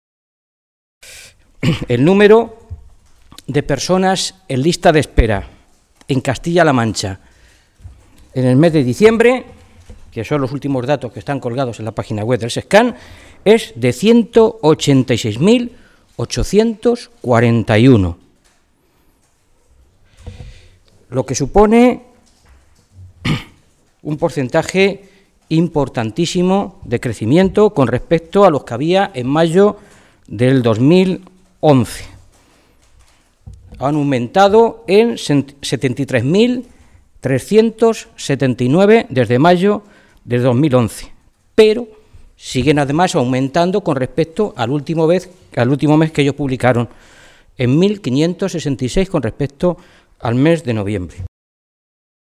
Fernando Mora, portavoz de Sanidad del Grupo Socialista
Mora hacía estas consideraciones en rueda de prensa, esta mañana, en Toledo, en la que, con esos datos avalados por el propio Ejecutivo de Cospedal, se puede observar cómo “el número de pacientes en lista de espera para una operación quirúrgica, una prueba diagnóstica o una consulta externa ha crecido en más de 73.000 personas, un 65 por ciento más, entre mayo de 2011, cuando Cospedal llegó al Gobierno, y diciembre de 2012”, fecha de los últimos datos publicados por el SESCAM.
Cortes de audio de la rueda de prensa